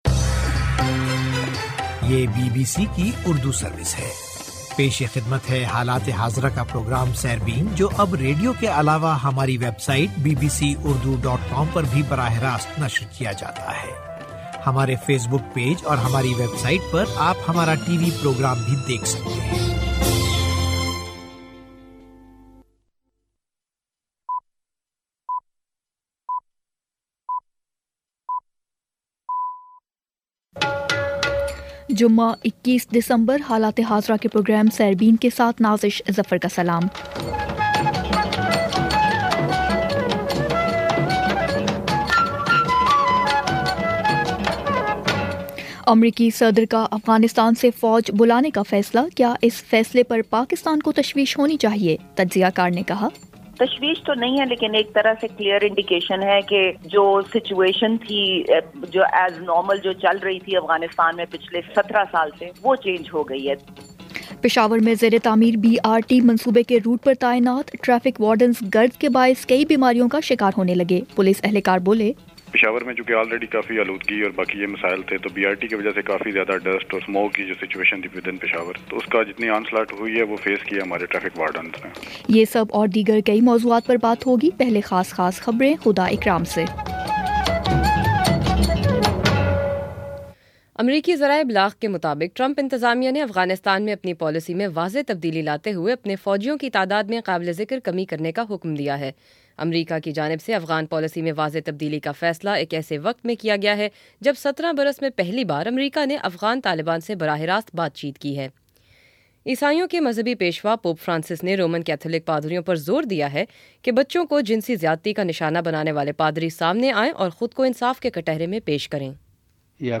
جمعہ 21 دسمبر کا سیربین ریڈیو پروگرام
بی بی سی اردو کا فلیگ شپ ریڈیو پروگرام روزانہ پاکستانی وقت کے مطابق رات آٹھ بجے پیش کیا جاتا ہے جسے آپ ہماری ویب سائٹ، اپنے موبائل فون، ڈیسک ٹاپ، ٹیبلٹ، لیپ ٹاپ اور اب فیس بُک پر سن سکتے ہیں